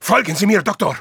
Voice file from Team Fortress 2 German version.
Spy_medicfollow02_de.wav